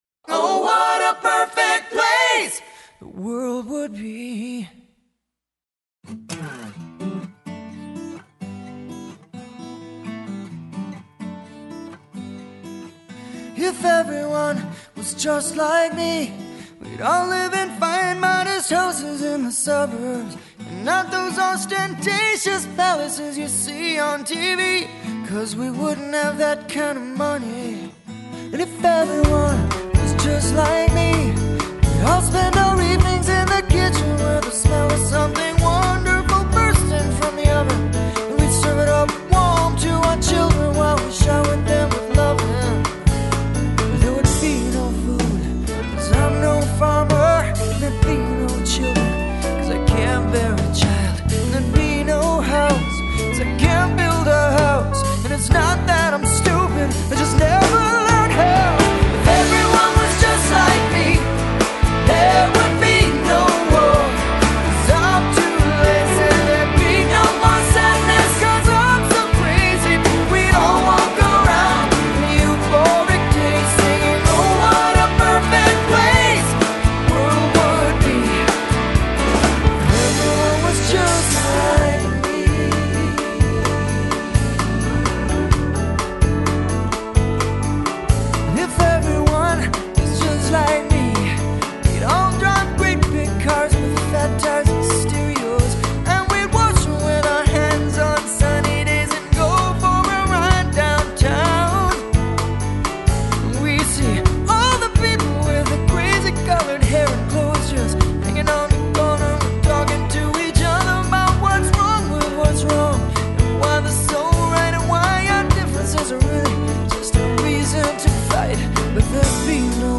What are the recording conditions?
the tempermill, ferndale, michigan